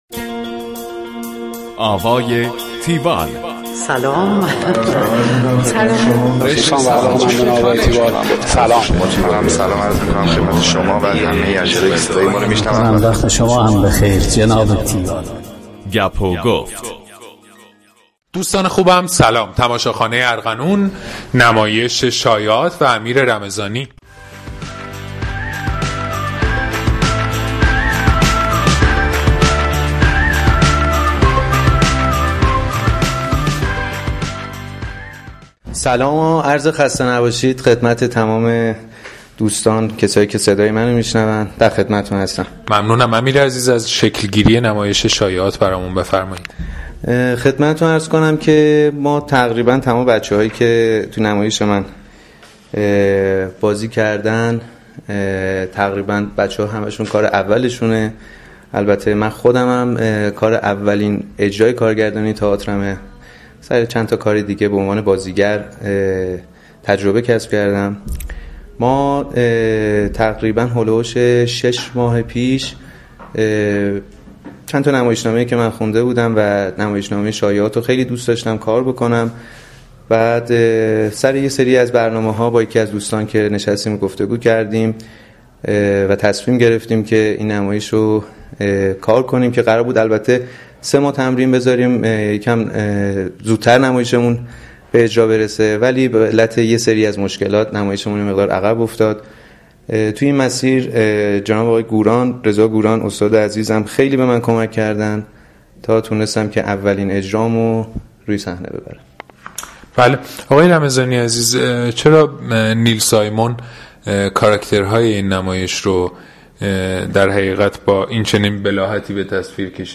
دانلود فایل صوتی گفتگوی تیوال